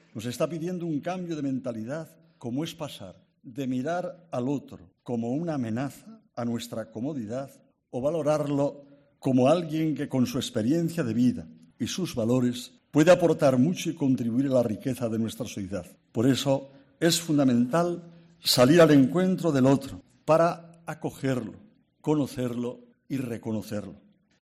Así se ha pronunciado durante su discurso en la presentación del Global Compact sobre Migrantes, junto al presidente de la Comunidad de Madrid, Ángel Garrido, y la alcaldesa de la ciudad, Manuela Carmena, en referencia al buque humanitario "Aquarius".